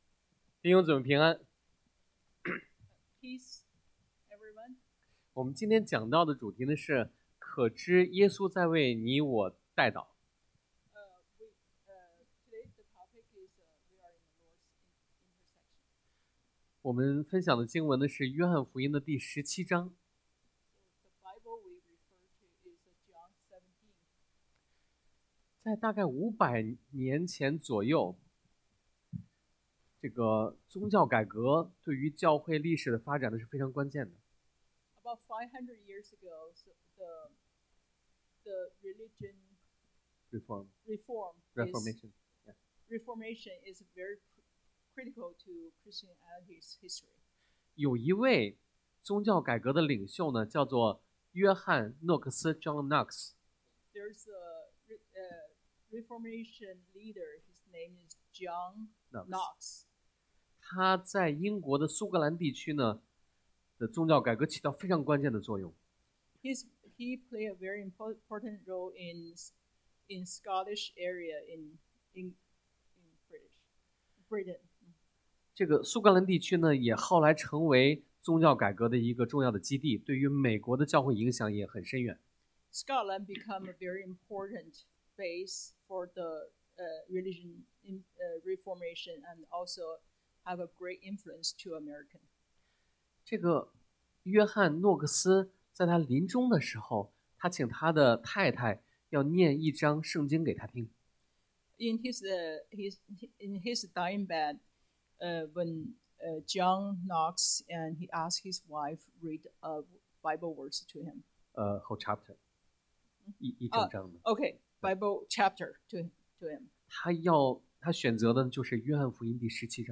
約翰福音 John 17 Service Type: Sunday AM 引言 Introduction